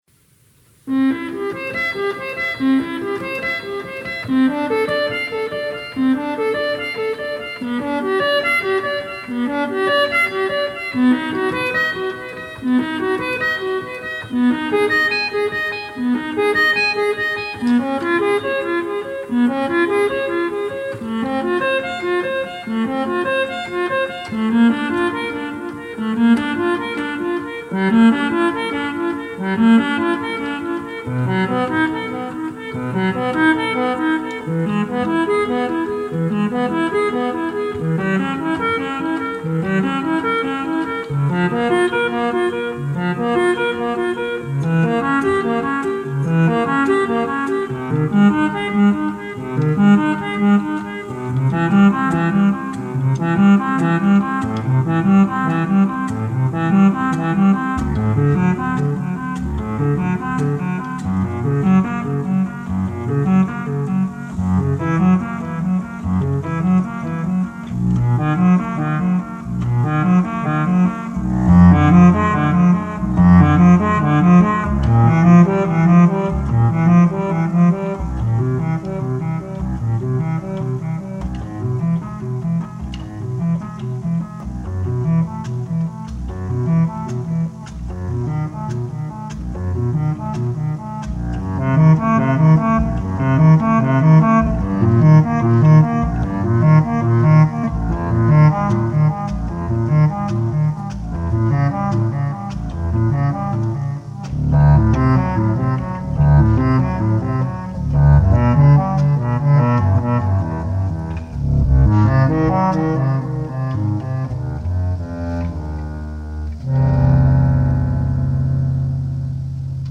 6-tone-harmonium
sixth-tone harmonium